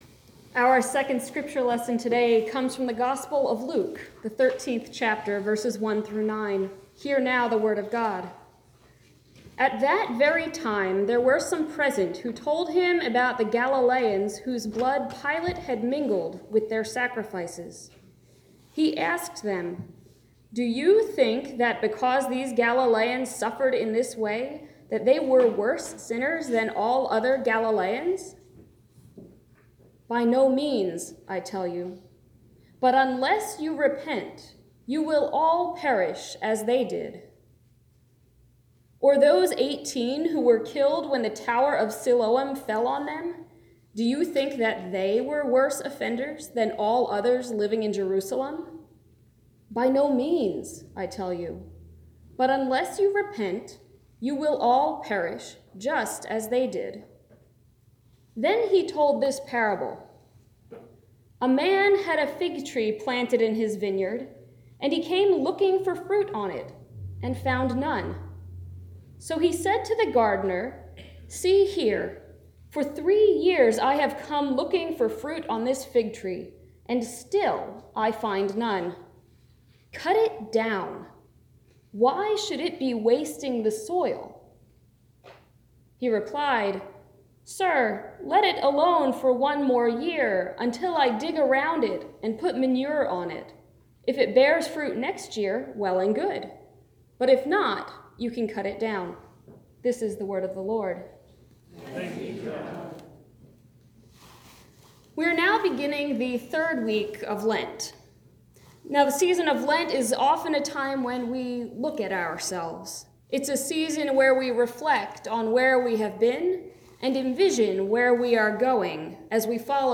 Preached at Faith United Presbyterian Church, Penn Argyl, PA on 3/24/19